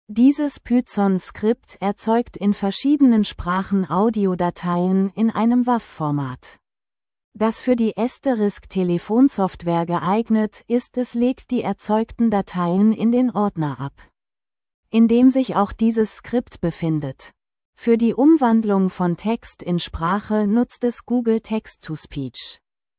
Klangbeispiele: Google gTTS in seiner kostenlosen Versionen ist nur für kurze Sätze geeignet, da es sich sonst oft in der Betonung vergreift.
Diese wird zunächst als MP3 erstellt und dann mit Hilfe von ffmpeg in eine WAV-Datei mit Asterisk-kompatiblen Einstellungen (8 kHz, Mono) umgewandelt.